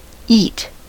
eet: Wikimedia Commons US English Pronunciations
En-us-eet.WAV